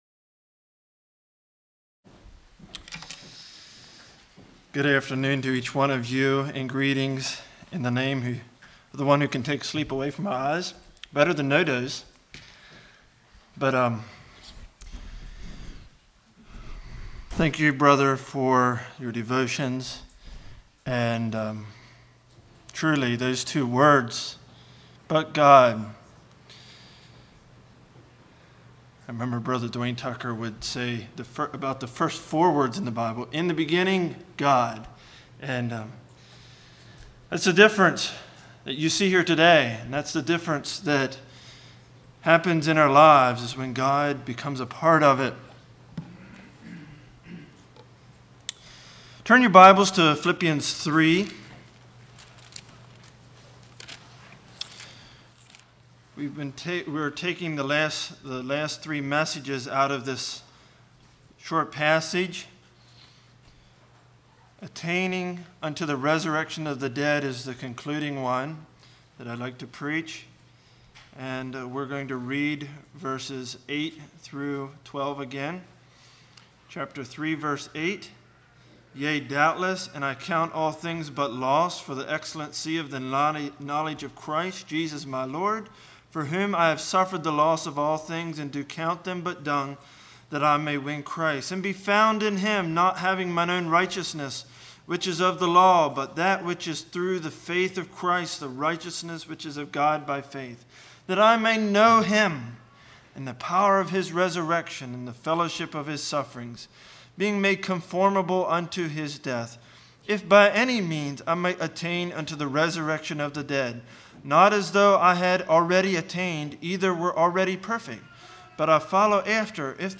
Evangelistic